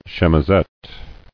[chem·i·sette]